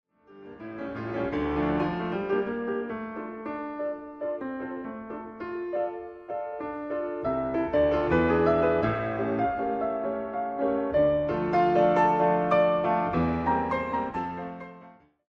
pdfmp3Chasselon, Mélanie, Intermezzo for piano, Allegretto, mm.18-25 (link to recording)